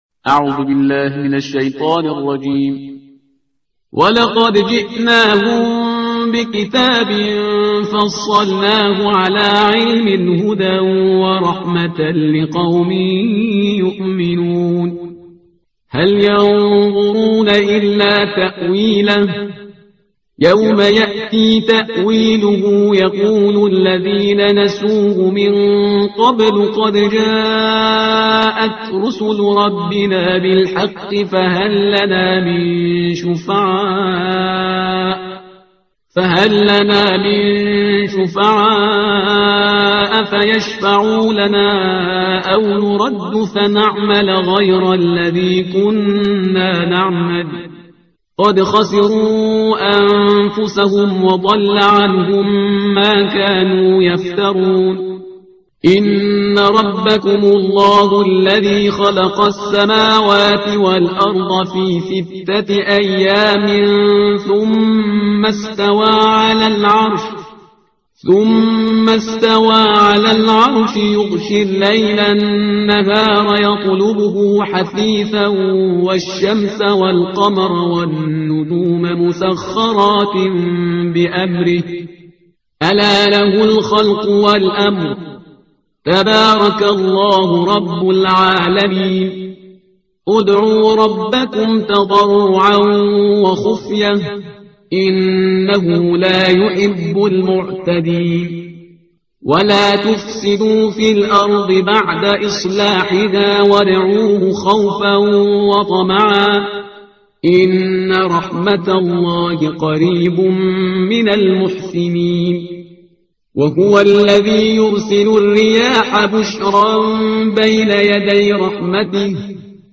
ترتیل صفحه 157 قرآن با صدای شهریار پرهیزگار